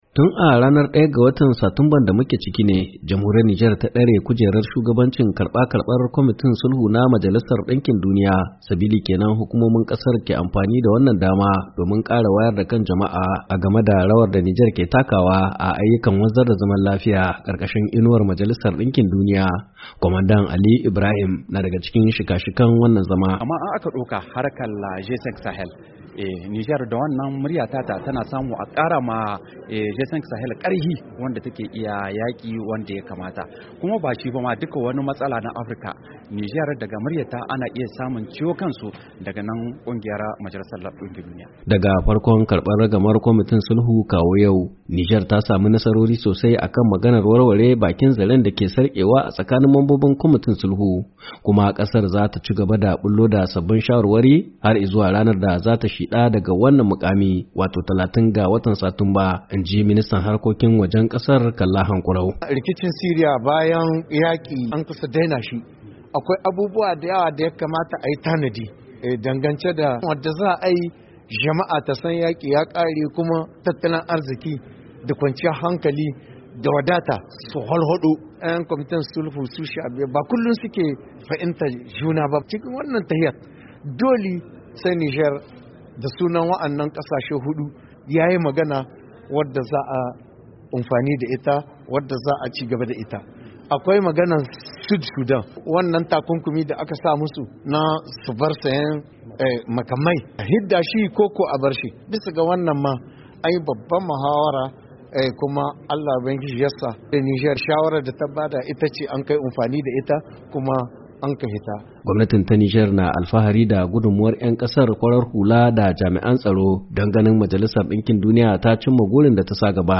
cikakken rahoton cikin sauti